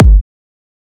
edm-kick-43.wav